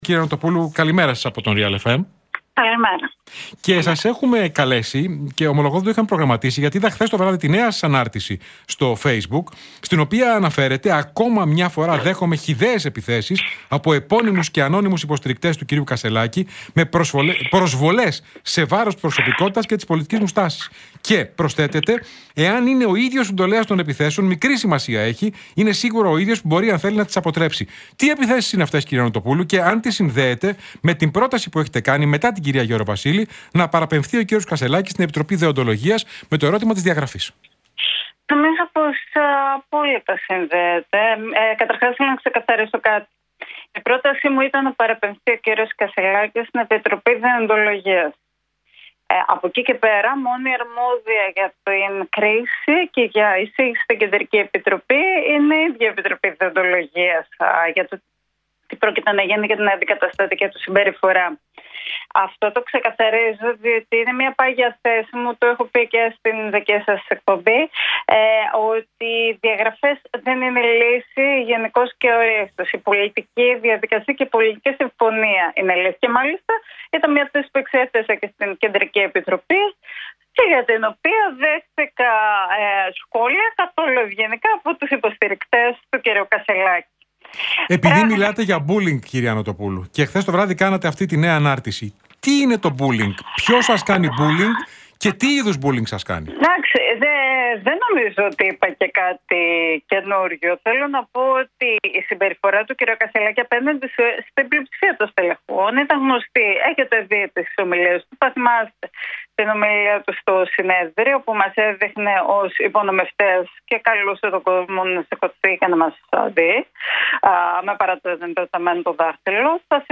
Νοτοπούλου στον Realfm 97,8: Αν συναινεί ο Κασσελάκης μπορούν να δημοσιοποιηθούν τα μηνύματα που μου έστειλε